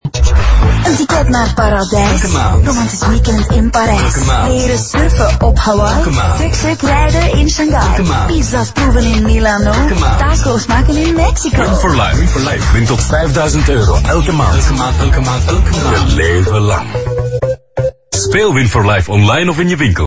The new visual and auditory identity of Win for Life became more playful, and the campaign now successfully engages a younger demographic.
A cheerful pink parrot that keeps repeating: You could win every month!